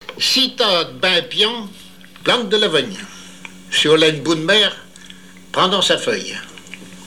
Patois local
Genre dicton
émission La fin de la Rabinaïe sur Alouette